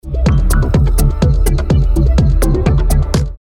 Закидываю вавку в новый проект и начинается магия ))) Во время работы с лупом (самое жирное место в треке по кругу) заметил, что иногда глотаются звуки. Играет пять, десять проходов нормально, но на 11 проход кик проглотился, например.